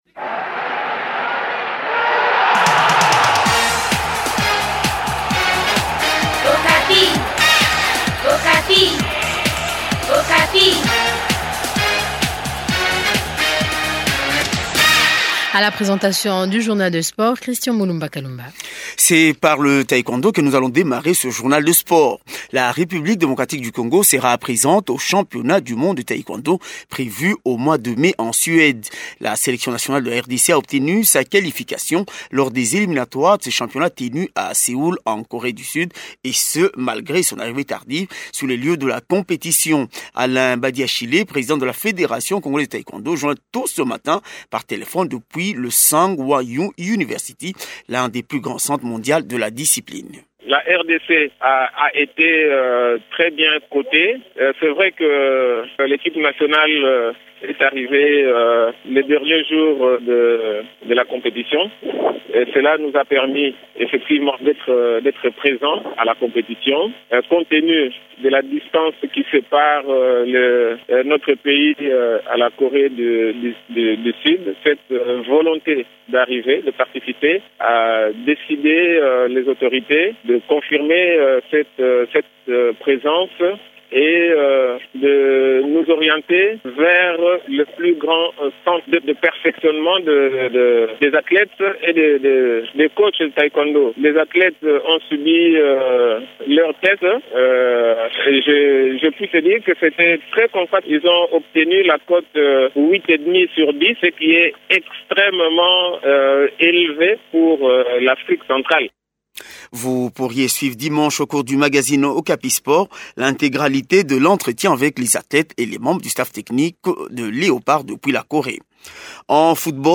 C’est par le Taekwondo que nous démarrons ce magazine des sports. La République Démocratique du Congo sera présente au championnat du monde de Taekwondo prévu au mois de mai prochain en Suède.